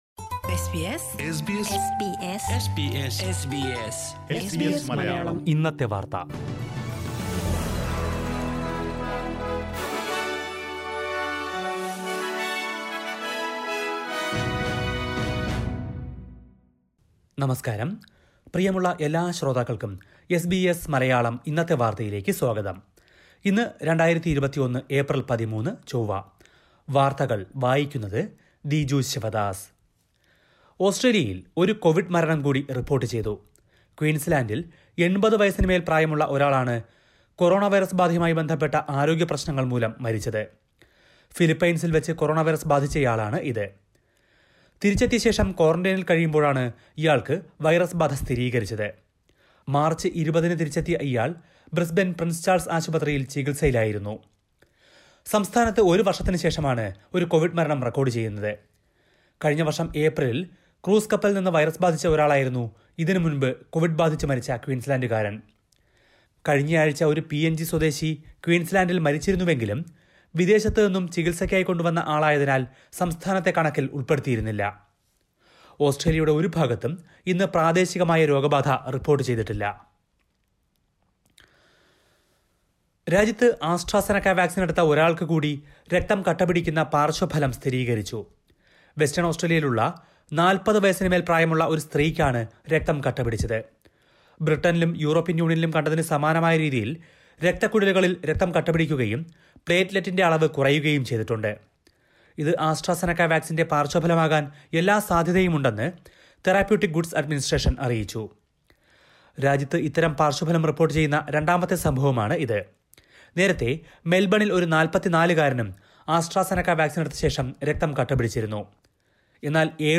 2021 ഏപ്രിൽ 13ലെ ഓസ്ട്രേലിയയിലെ ഏറ്റവും പ്രധാന വാർത്തകൾ കേൾക്കാം..